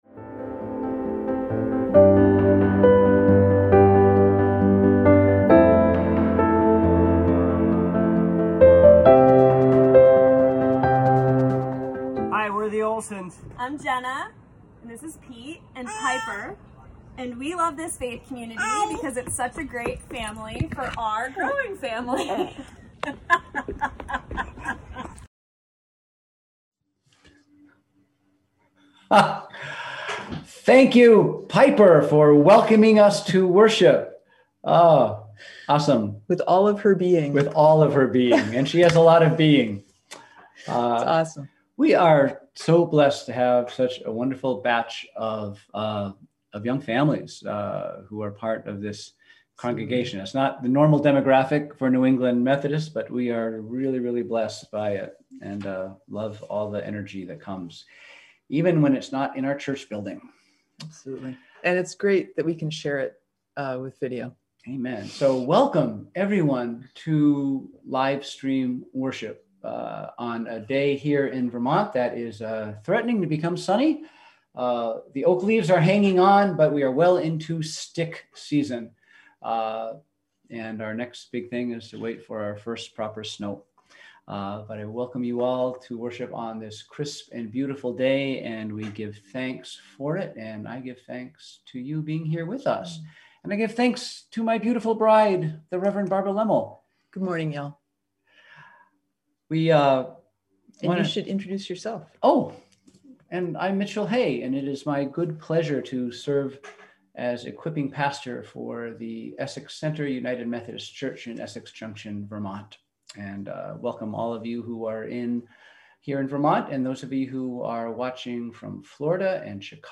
We held virtual worship on Sunday, October 25, 2020 at 10am.